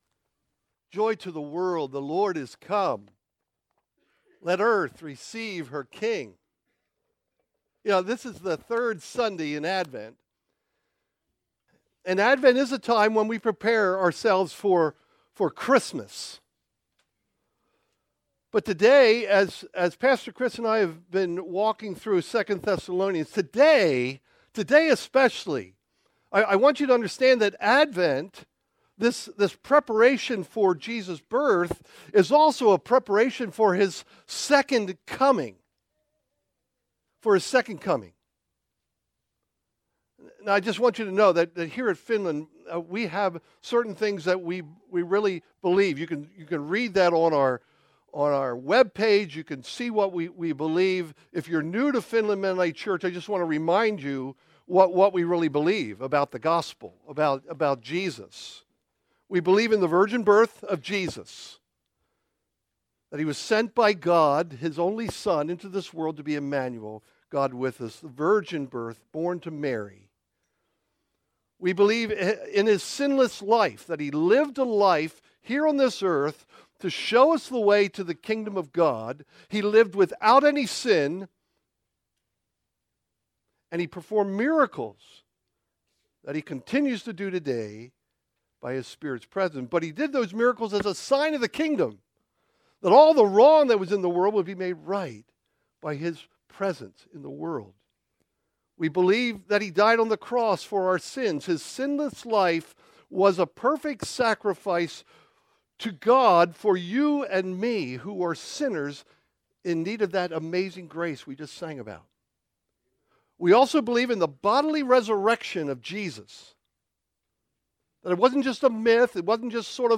Faithful One - Sermon Series